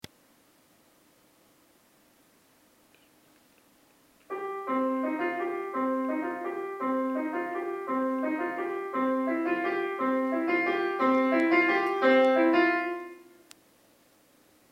- Compás: 6/8.
- Tonalidad: Do menor
Piano